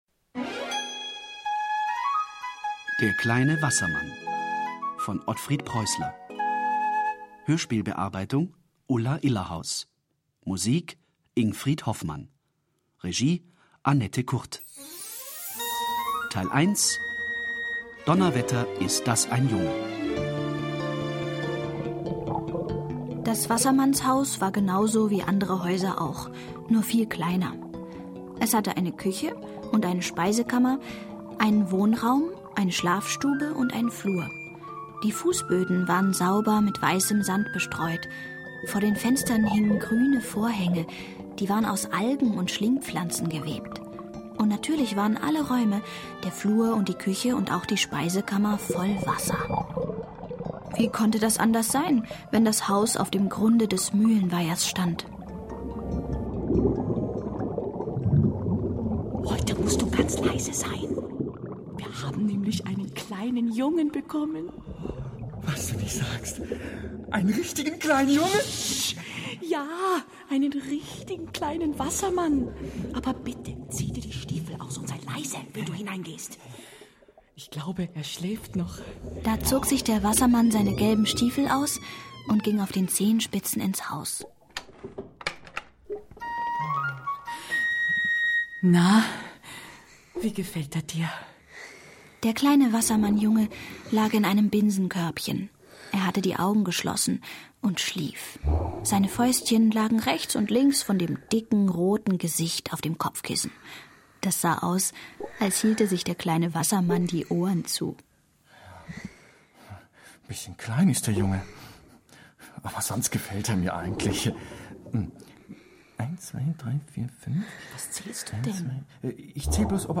Hörspiel für Kinder (2 CDs)
Die Hörspielfassung mit ihren vielen Sprechern, Geräuschen und Liedern sprudelt nur so über von Einfällen, Witz und Fantasie.